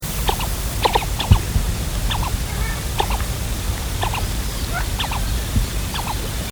Anas platalea - Pato cuchara